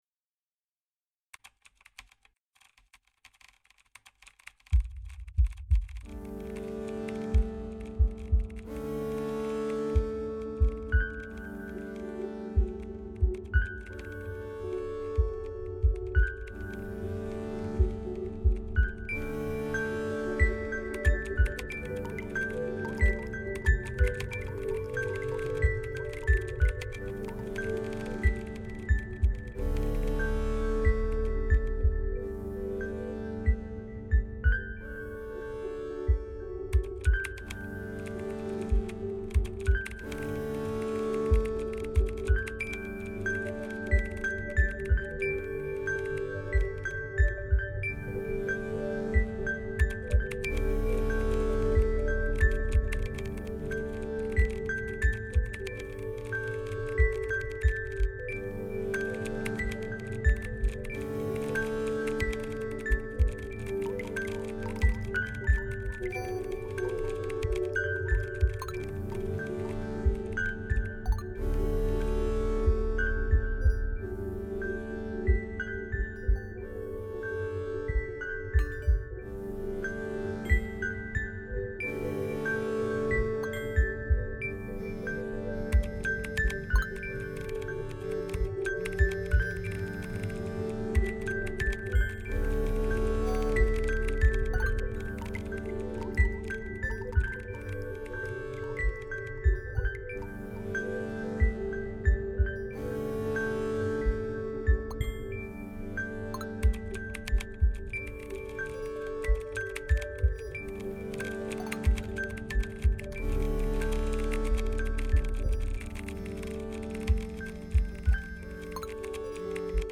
In short, Let’s Never Meet is about meeting people over the internet. The soundtrack is actually a remix of a an Android alarm ring tone.
It’s not an alarm tone that I use myself but it was ambient enough to work in an outdoor setting for an extended period without getting annoying.
It was pretty samey throughout and I think there needed to be some kind of buildup or change in pace.
lnm_nodrums.mp3